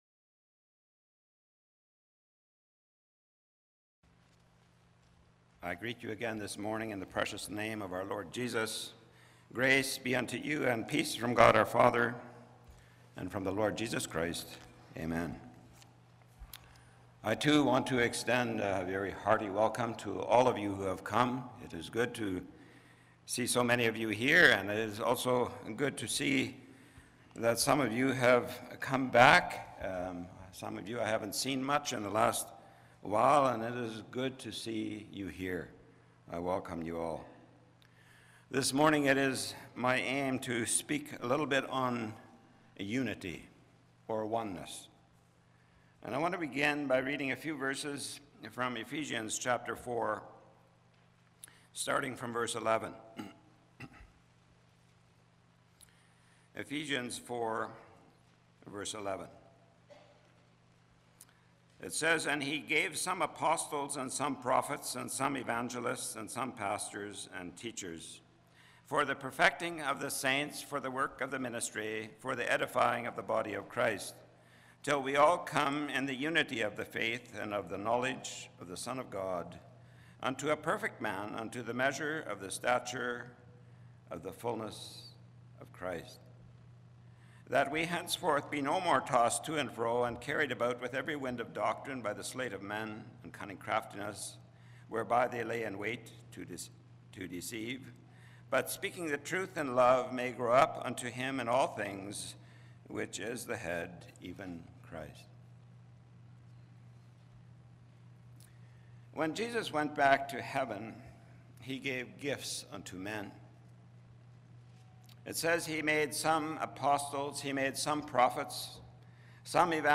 Ephesians 4:11-16m Philippians 2:1-4 Service Type: Sunday Morning Topics